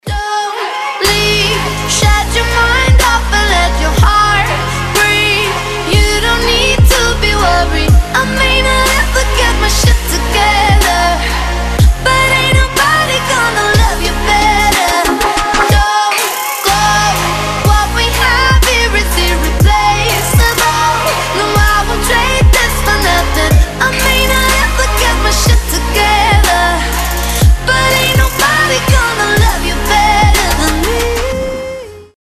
женский вокал
Electronic
Trap
vocal